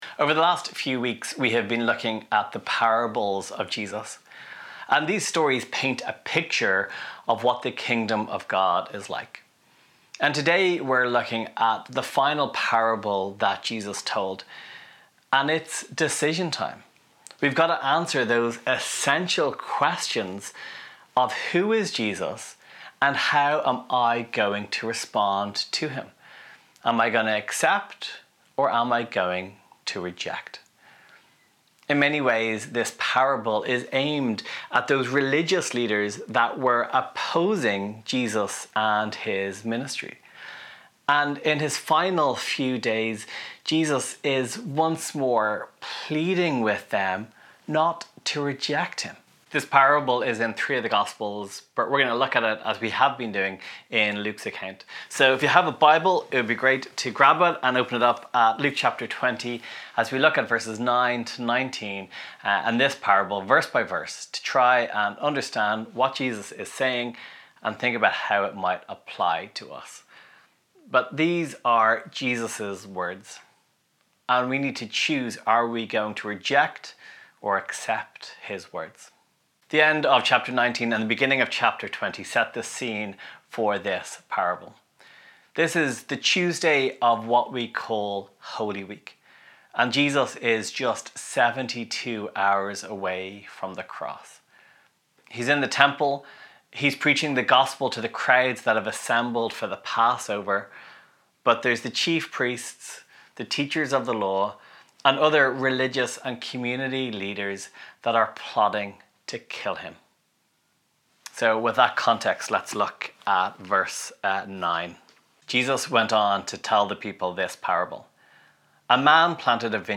Downloads March/Easter Reading Plan Download Sermon MP3 Share this: Share on X (Opens in new window) X Share on Facebook (Opens in new window) Facebook Like Loading...